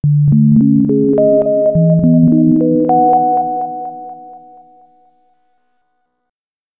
Here's some Echo slider examples to illustrate the difference in sound:
EXAMPLE 2: Equal echo slider values:
c700_echo_equalvalues.mp3